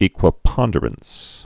(ēkwə-pŏndər-əns, ĕkwə-)